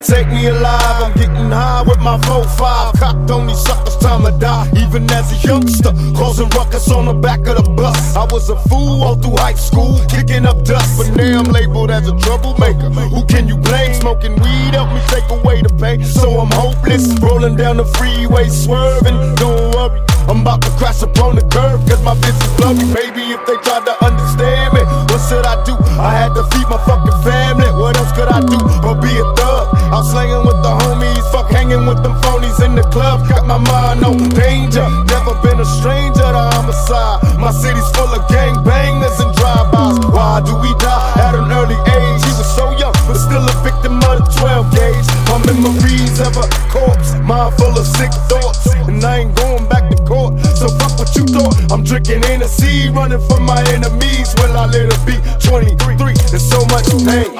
мужской вокал
Trap
Rap
Gangsta rap
Битовый ремикс